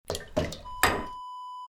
Sauna Ladle Set Down Wav Sound Effect #2
Description: The sound of setting down a sauna ladle
Properties: 48.000 kHz 16-bit Stereo
A beep sound is embedded in the audio preview file but it is not present in the high resolution downloadable wav file.
Keywords: sauna, ladle, bucket, water, scoop, foley, copper, metal, set, setting, put, putting, down
sauna-ladle-set-down-preview-2.mp3